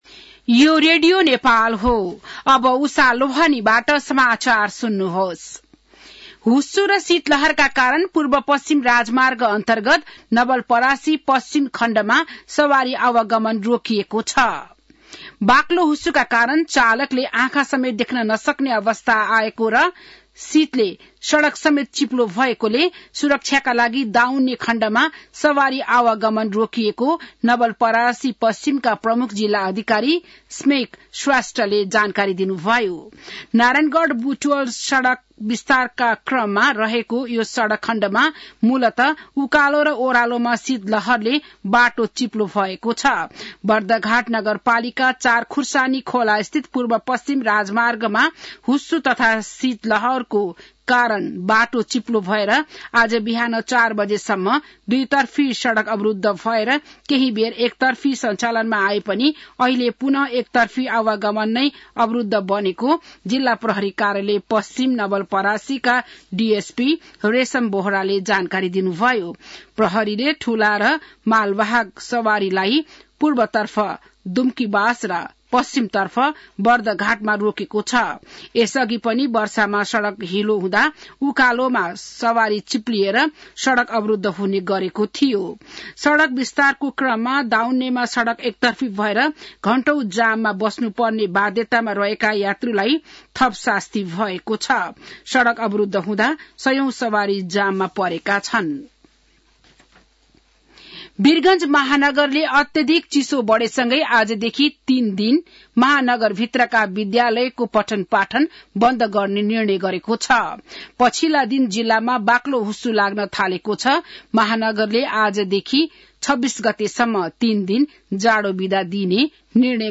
बिहान १० बजेको नेपाली समाचार : २५ पुष , २०८१